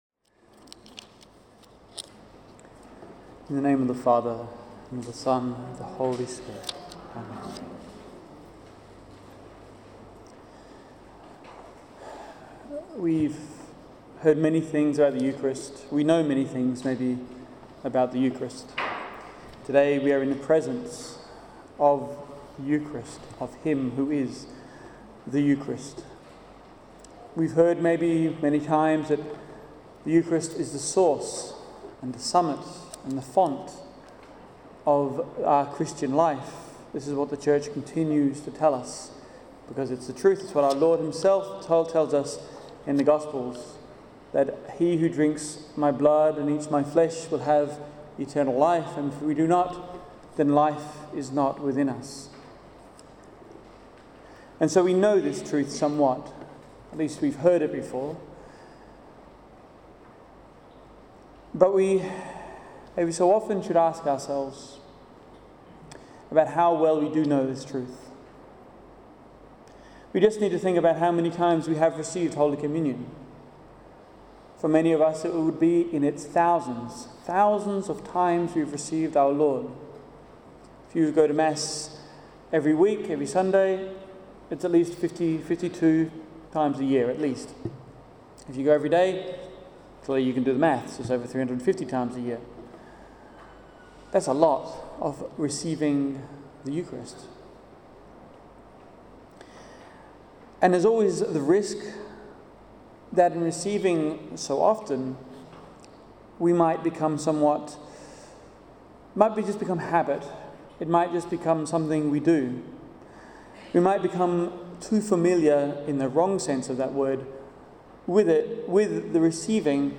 held at St. Pius X Parish in Manning, Western Australia